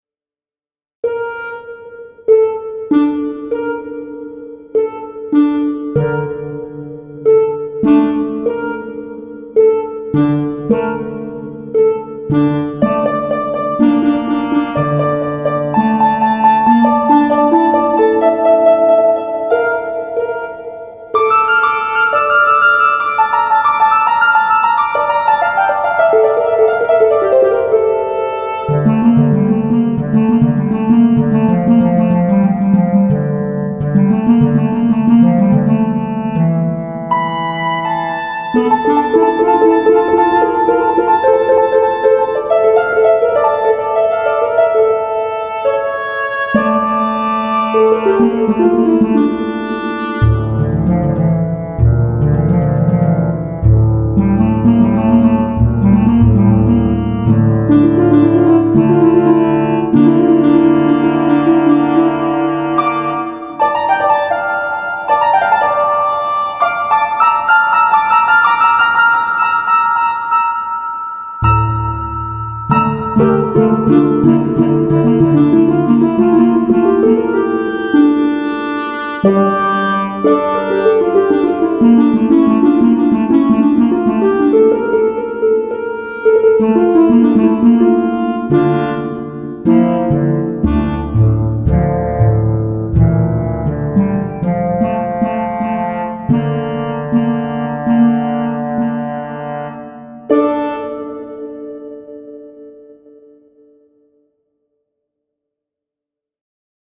Posted in Classical Comments Off on